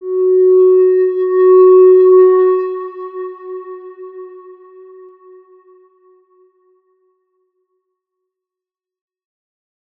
X_Windwistle-F#3-pp.wav